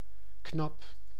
Ääntäminen
IPA: /knɑp/